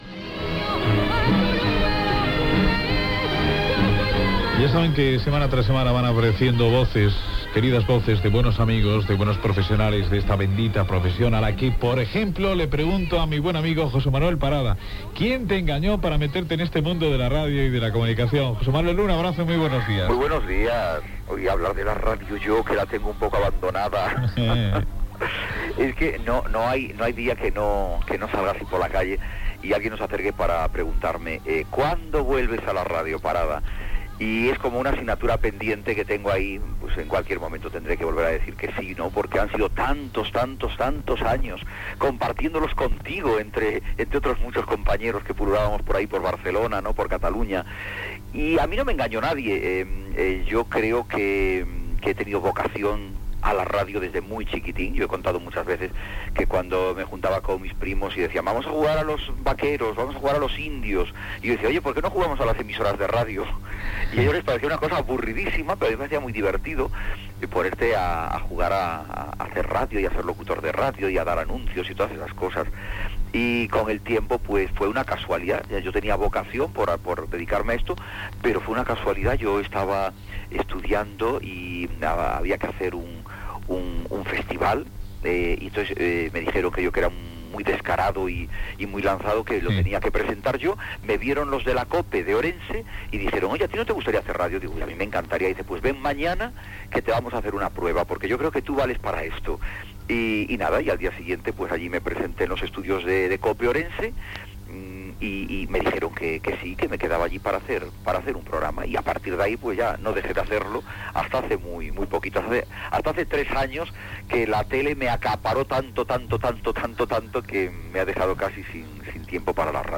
4da2199fe0af67b1a0949ff599b31457c3d258f7.mp3 Títol Ràdio Terrassa-SER Vallès Emissora Ràdio Terrassa-SER Vallès Cadena SER Titularitat Privada local Nom programa Hoy por hoy Catalunya Descripció Secció "Mi querida ràdio" amb una entrevista al presentador José Manuel Parada sobre la seva trajectòria professional, amb la intervenció de Chelo García Cortés.